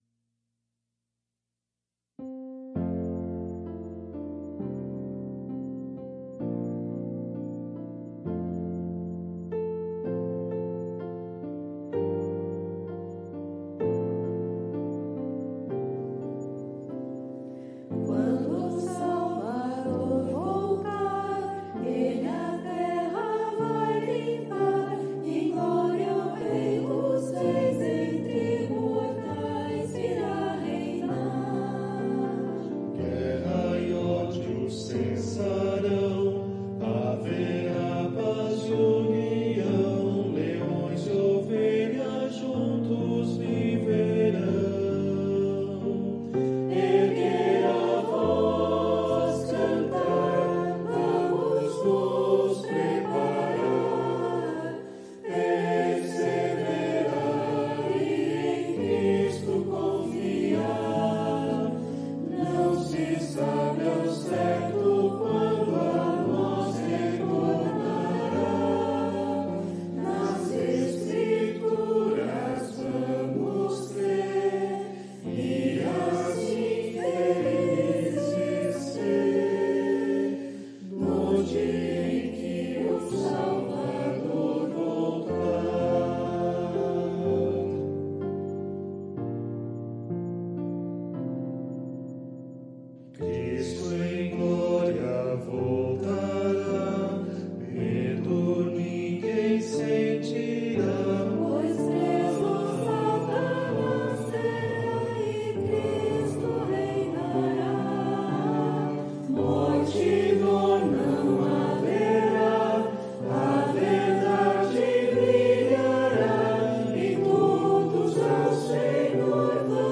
Voicing/Instrumentation: SAB